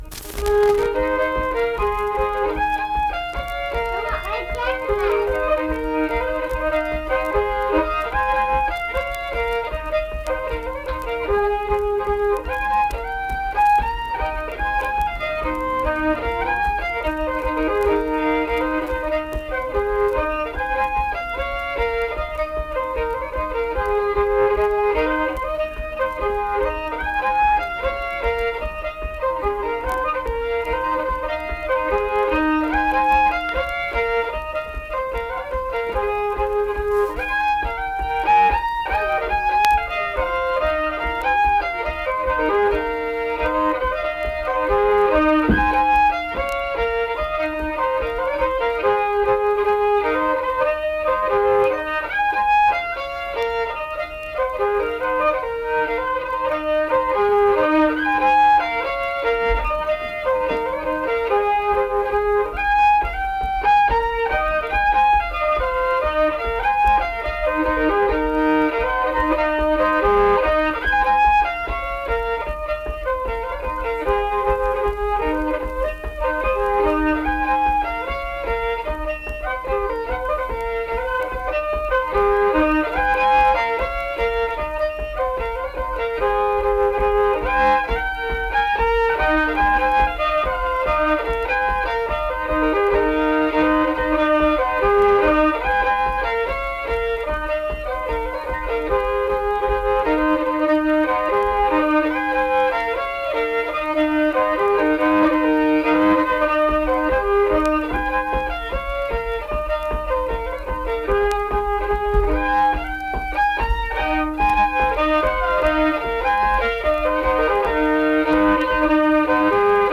Unaccompanied fiddle music
Instrumental Music
Fiddle
Marlinton (W. Va.), Pocahontas County (W. Va.)